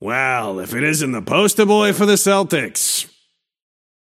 Shopkeeper voice line (to Lash) - Wow, if it isn't the poster boy for the Celtics!